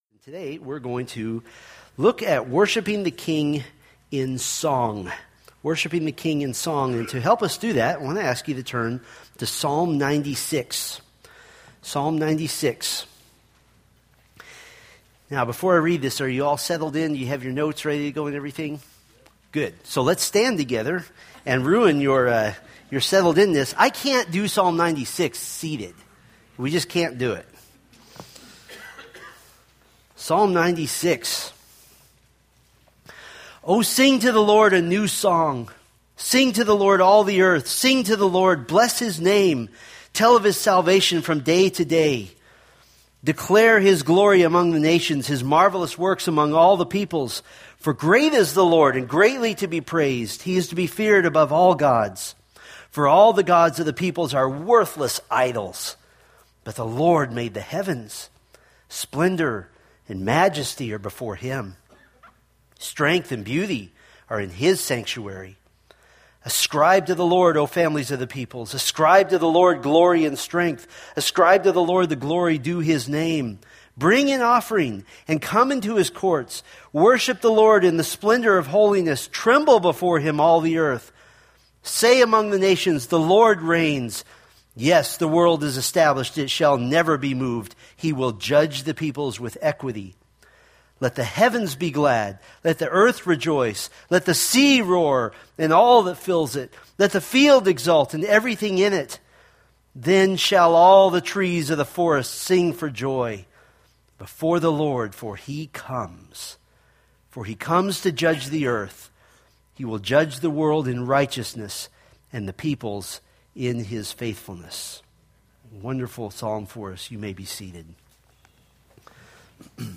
Psalms Sermon Series